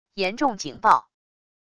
严重警报wav音频